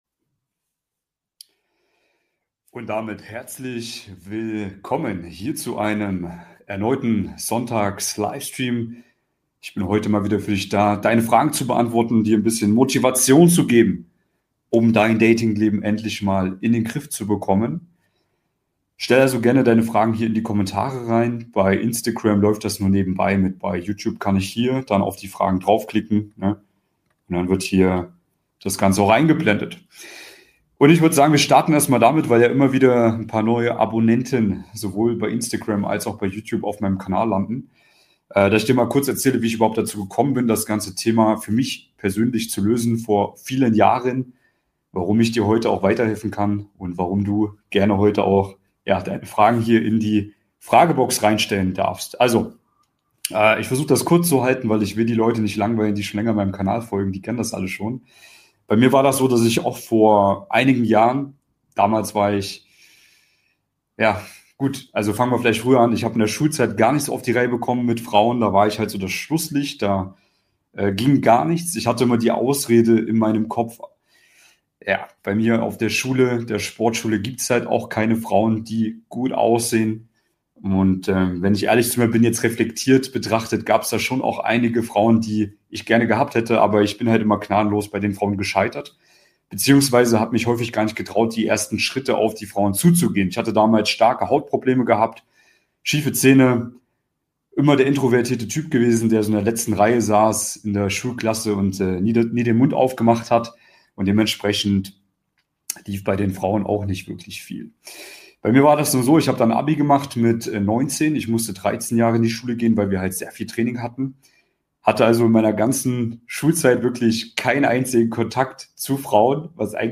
In diesem Livestream beantworte ich Deine brennendsten Fragen rund um das Thema Dating.